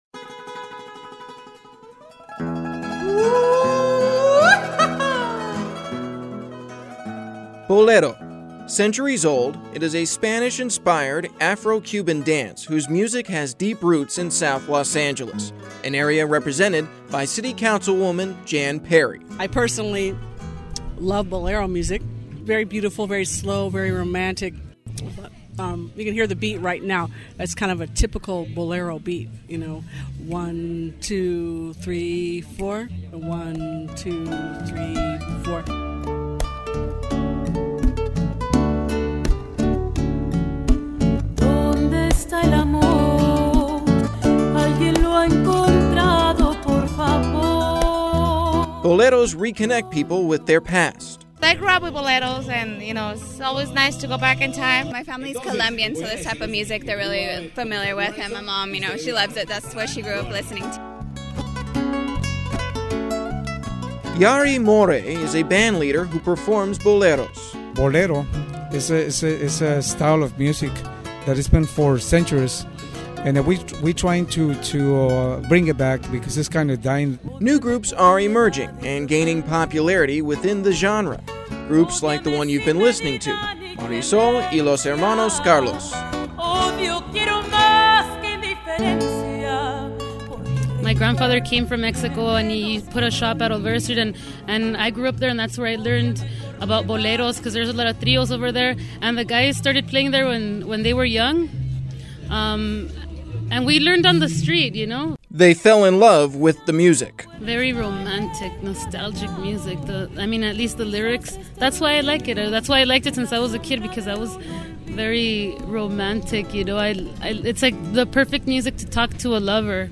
audio story